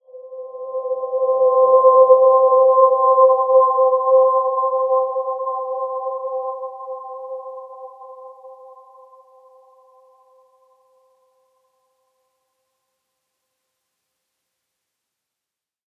Dreamy-Fifths-C5-p.wav